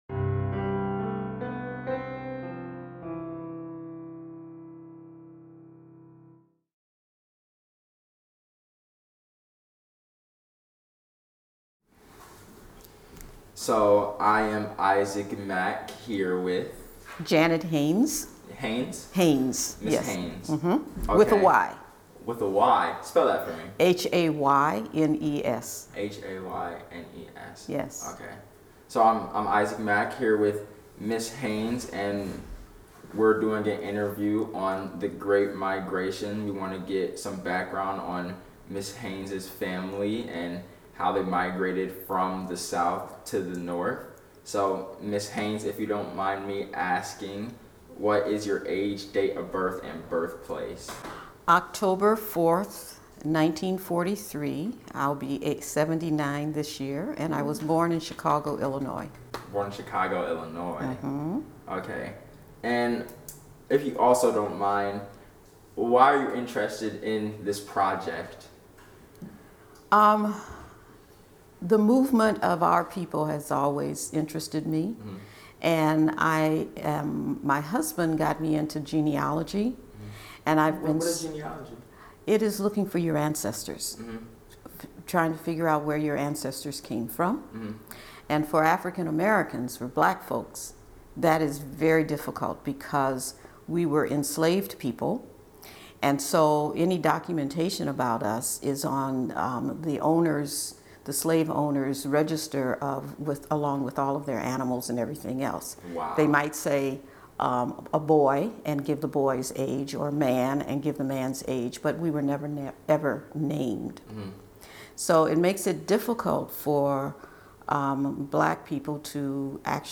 July 30, 2022 at African American Cultural & Historical Museum of Washtenaw County
Oral Histories